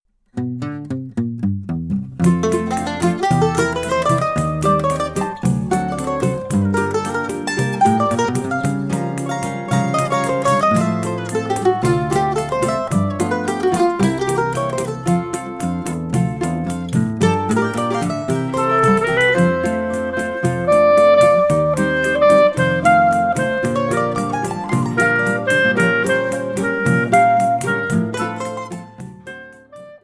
clarinete
Estúdio 185 – SP – Março e Abril de 2024